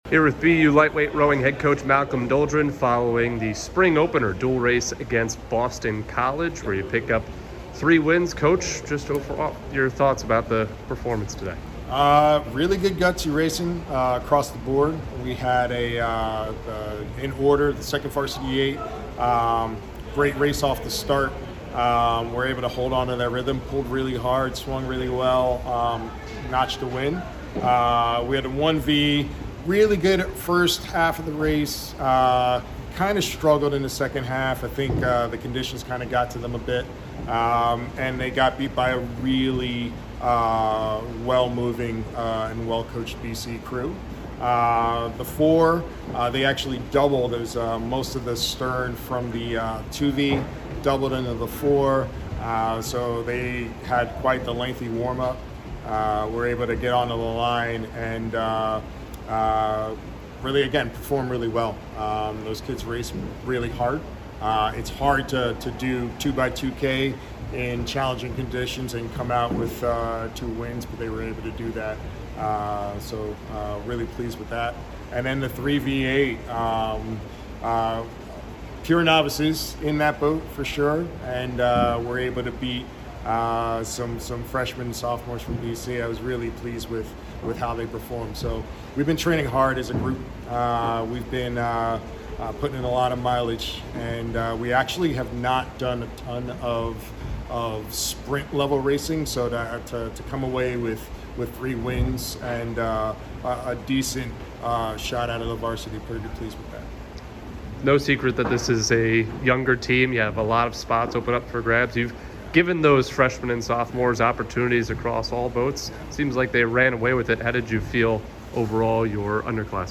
Lightweight Rowing / Boston College Postrace Interview